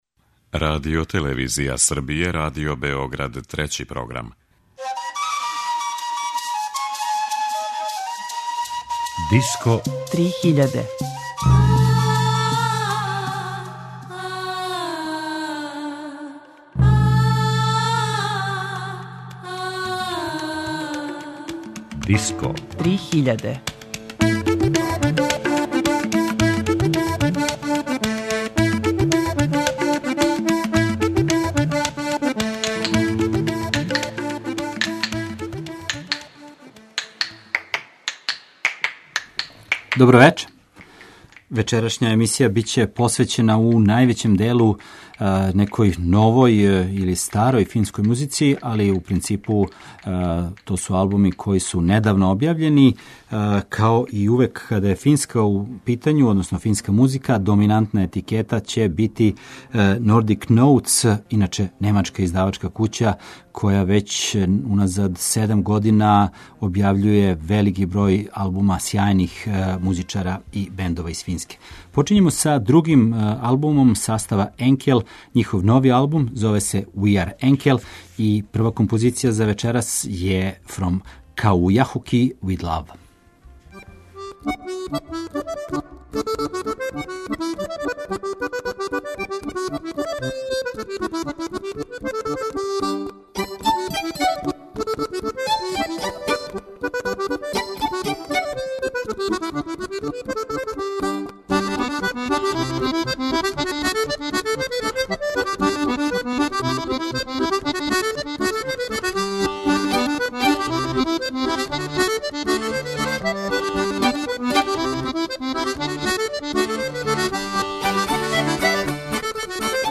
Финска world music сцена